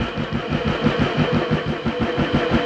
Index of /musicradar/rhythmic-inspiration-samples/90bpm
RI_DelayStack_90-05.wav